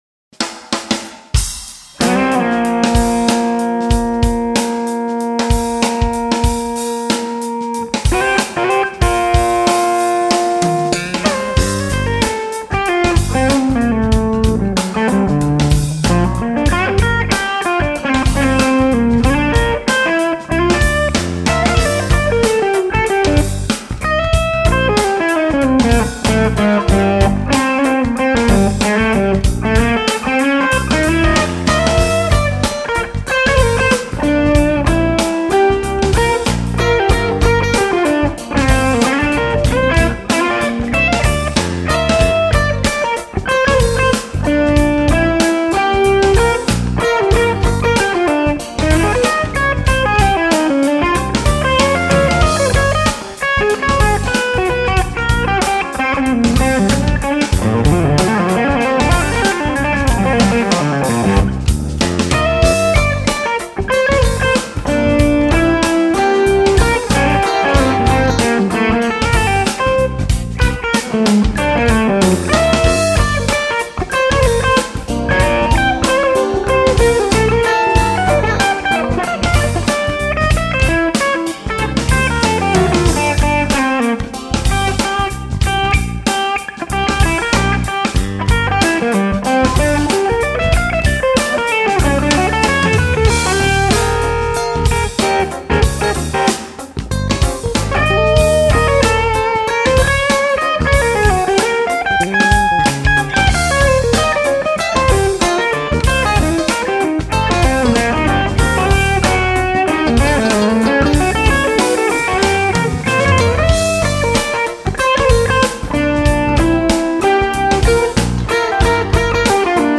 New Fuchs non HRM clip w/ off axis Royer mic
Royer mic set back about 6 inches. Turned about 60 degrees off axis. A bit dark, but you can hear the organic tone. Amp is brighter than the clip. Some was lost in the mic technique.
No delay. Just a touch of verb and 2db of EQ to make it cut since the clip was dark.
Amp is 100 watt 2003 Fuchs ODS.
I love the phrasing, very "sax" like. I like the flurry of notes at about 1:00, followed by a breath and some more great riffs. The double stops almost sound like some sax polyphonics.
Nice sax quality indeed!